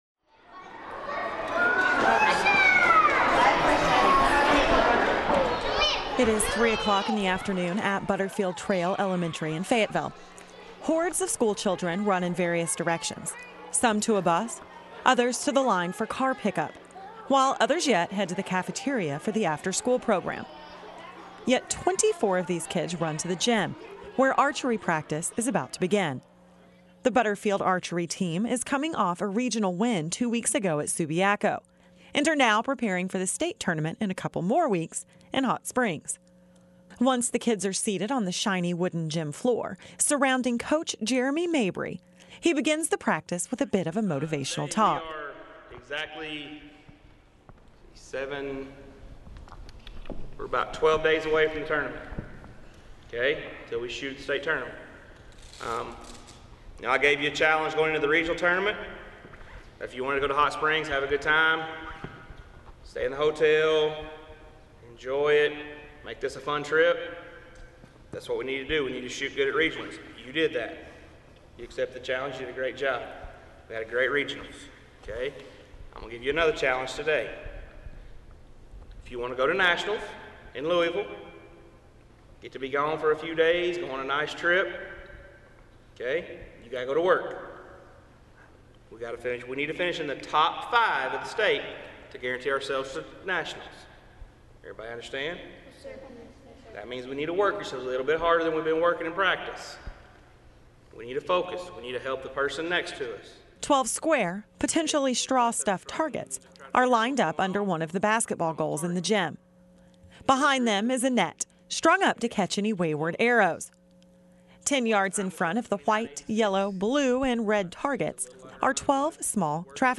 Inside Firmin-Garner Performance Studio
He talks about writing songs and plays a couple as well.